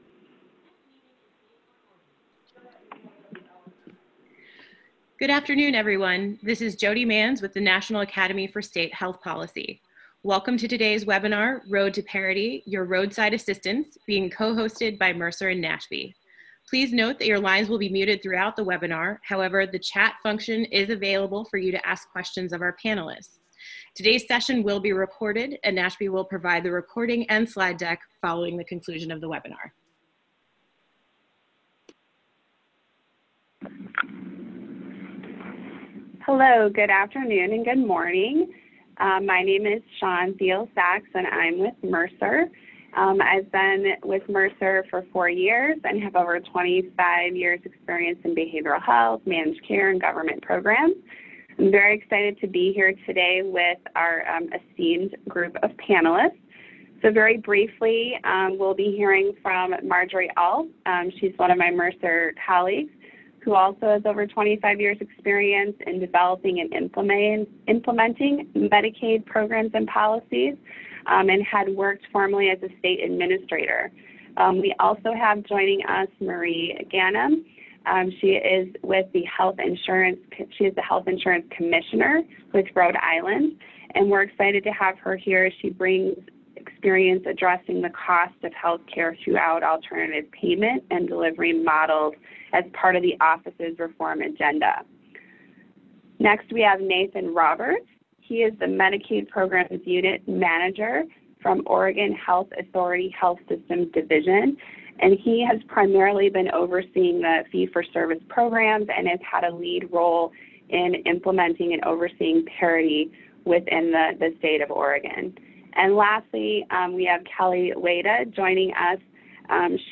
The webinar will feature state Medicaid and commercial insurance leaders from Rhode Island, Pennsylvania, and Oregon, as well as representatives from Mercer. Officials will share their insights into and experiences with commercial insurance markets.